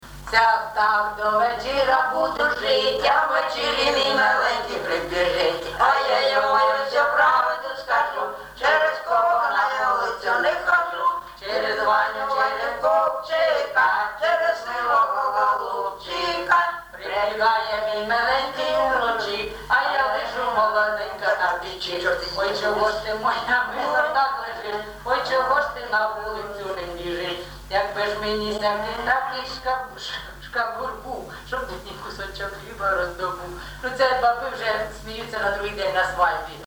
ЖанрЖартівливі
Місце записум. Єнакієве, Горлівський район, Донецька обл., Україна, Слобожанщина